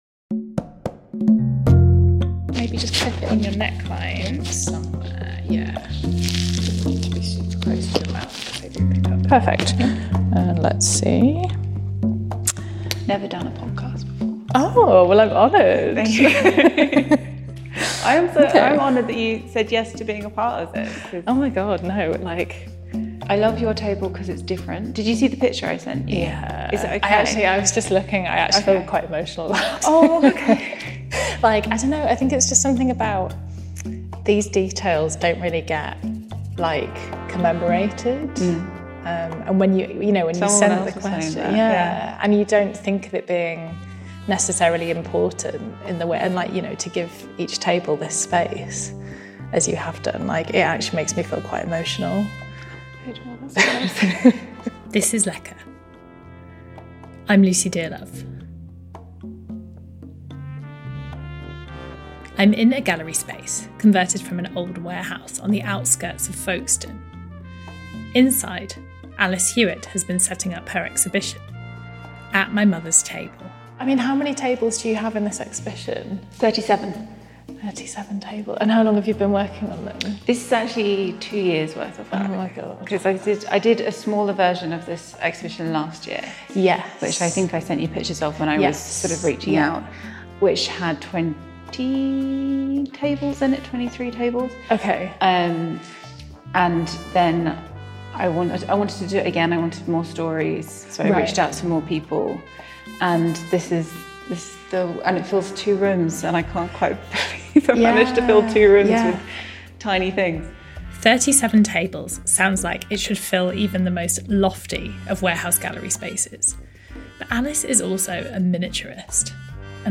Lecker is a podcast about how food shapes our lives. Recorded mostly in kitchens, each episode explores personal stories to examine our relationships with food – and each other.